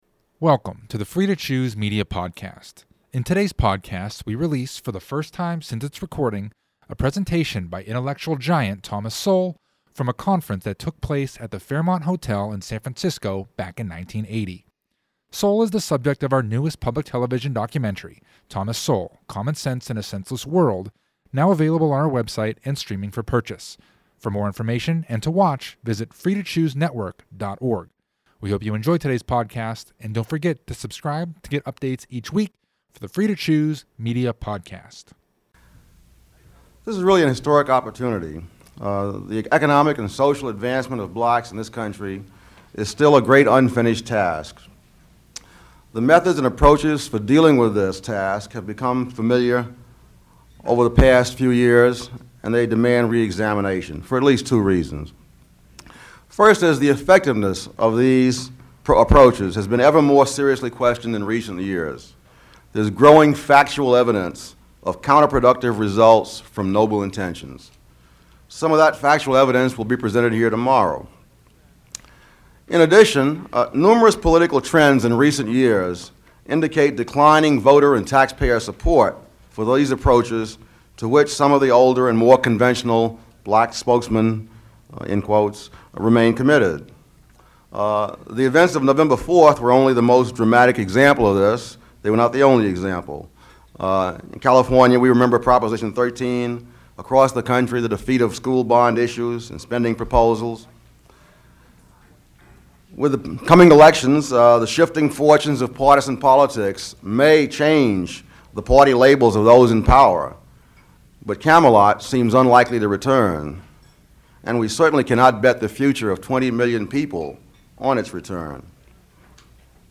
Episode 113 –Politics and Opportunity – Thomas Sowell at the Fairmont Conference